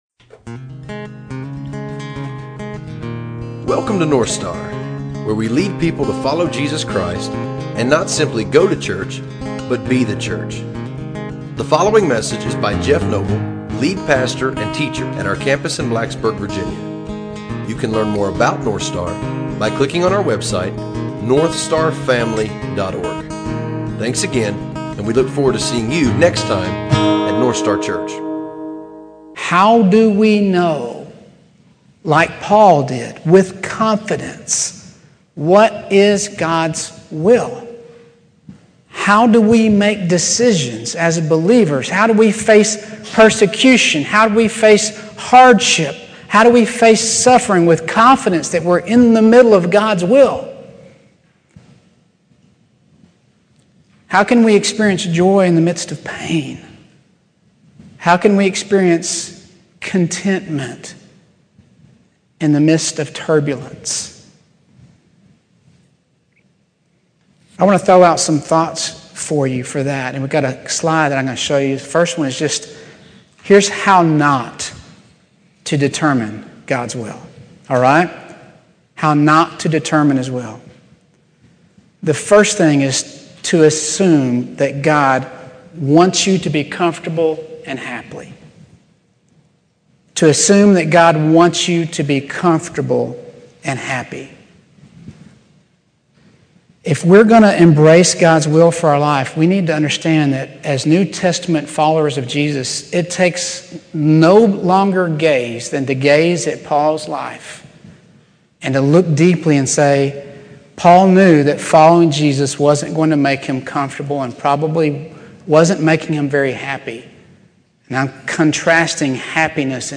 Last Sunday at our church, I preached a message entitled Weeping Over God’s Will from Acts 21:1-16. The context of the message was the apostle Paul’s gritty determination to pursue God’s will in his life, regardless of the fact that he knew it would lead him to persecution and arrest.